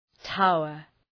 Προφορά
{‘taʋər}